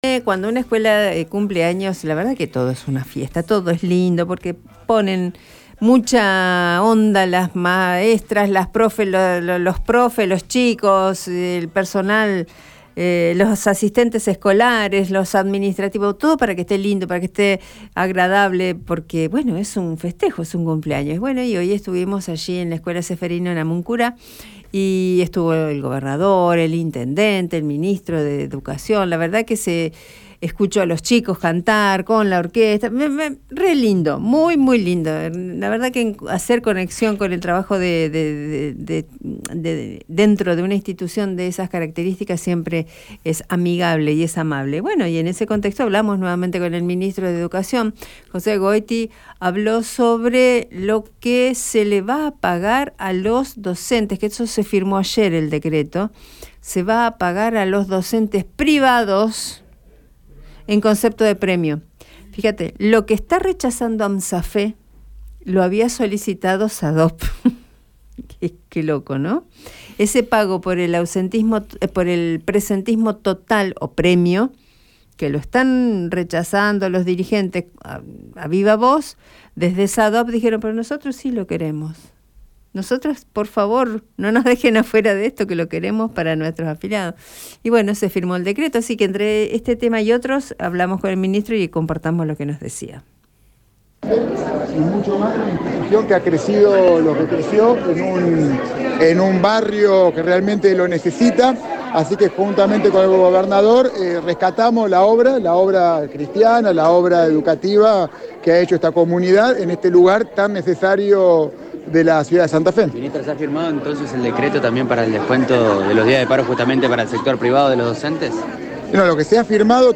Escucha lo que dijo José Goity en Radio EME: